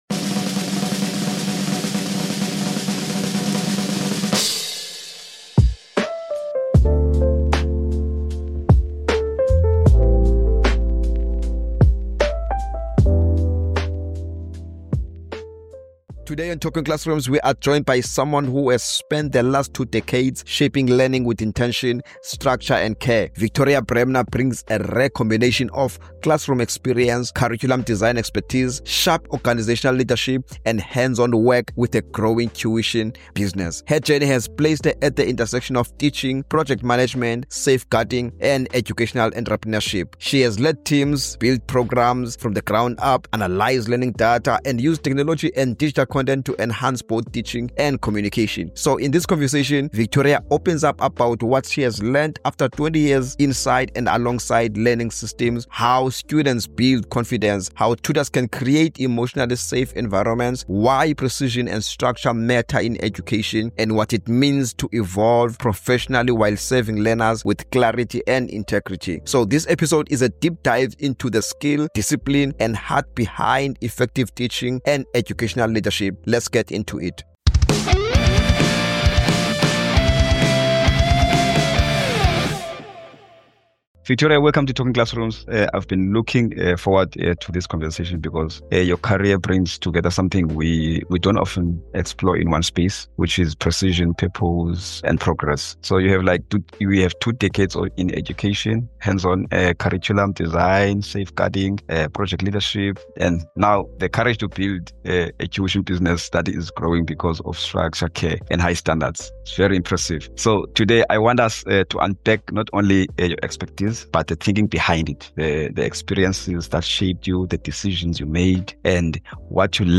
Our conversation explores how precision, organisation, and creativity shape effective teaching in a rapidly changing world.